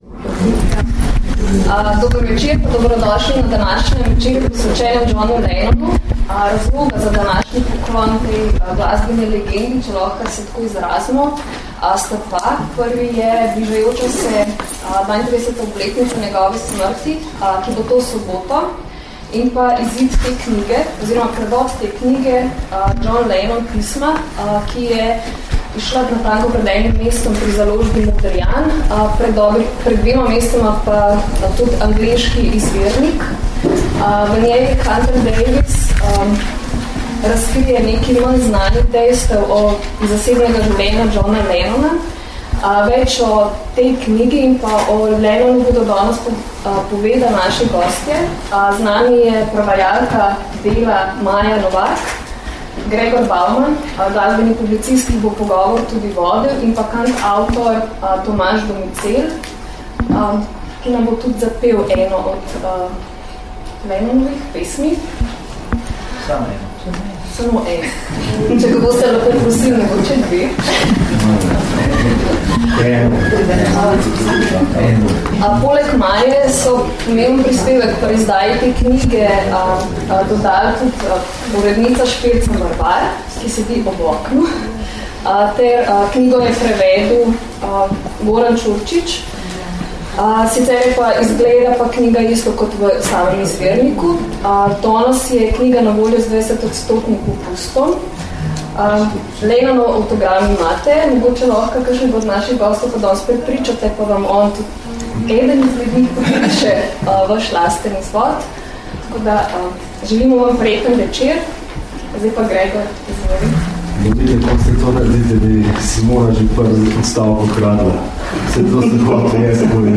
Ob 32. obletnici smrti Johna Lennona se je v četrtek, 6. decembra, v Modrijanovi knjigarni v Ljubljani odvil pogovor ob izidu knjige John Lennon – Pisma.